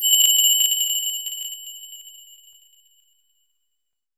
WIND BELL -S.WAV